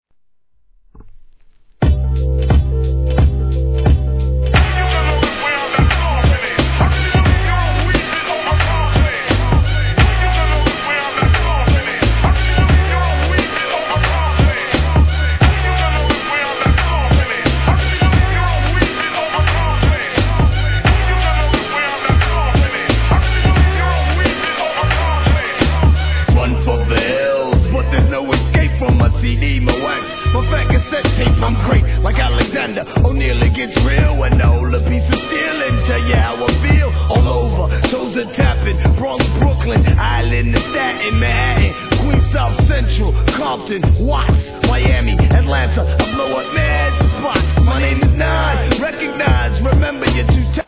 HIP HOP/R&B
'96年人気ミドル！！